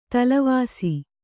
♪ talavāsi